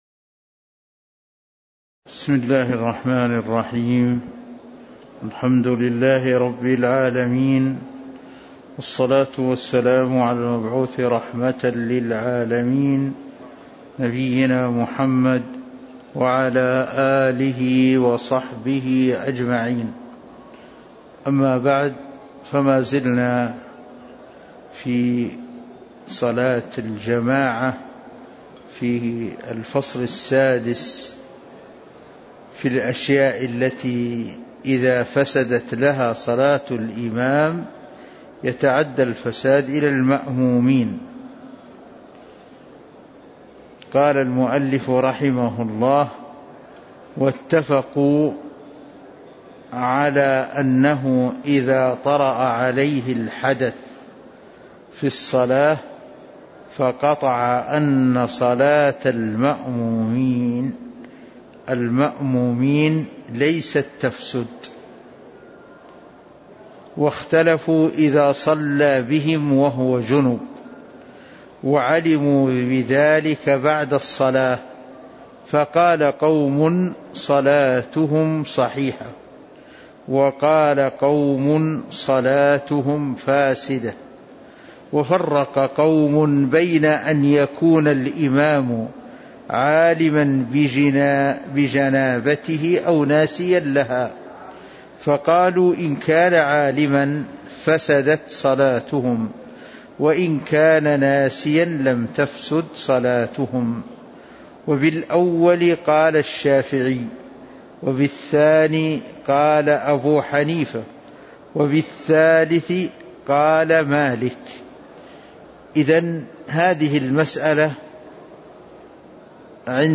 تاريخ النشر ١٦ ربيع الثاني ١٤٤٣ هـ المكان: المسجد النبوي الشيخ